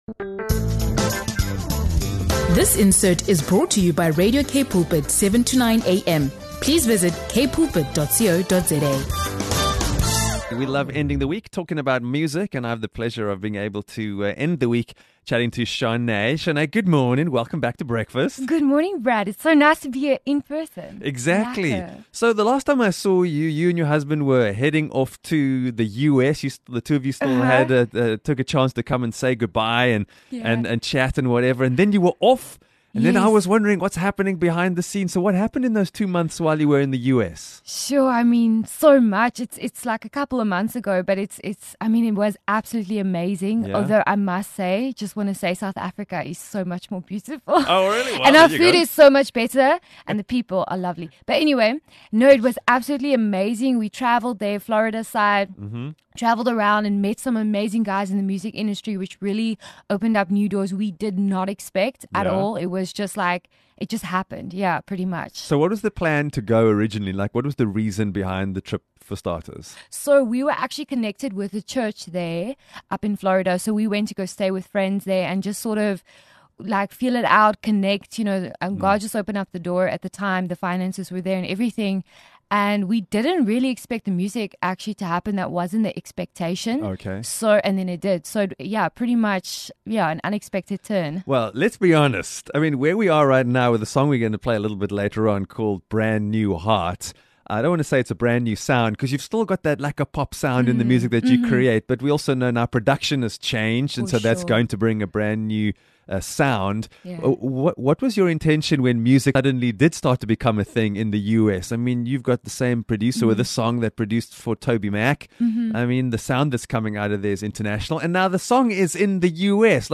In this inspiring interview